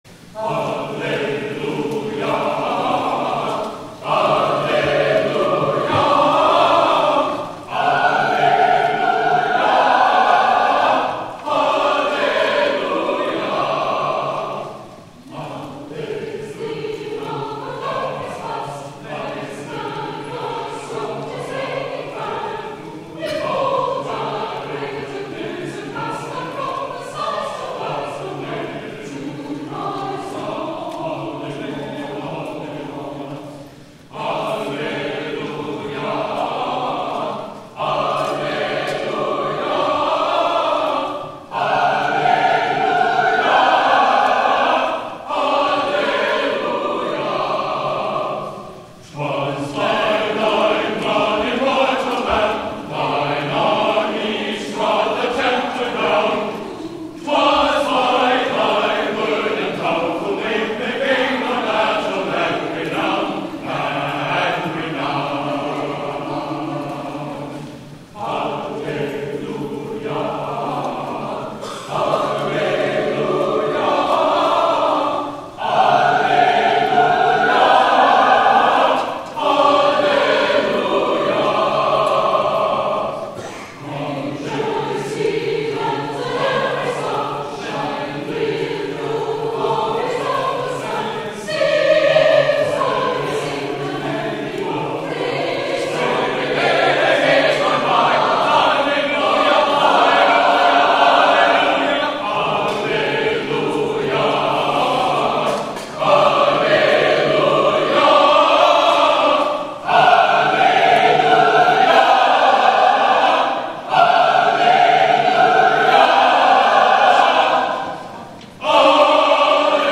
Easter Sunday
THE OFFERTORY
(11:00 a.m. worship)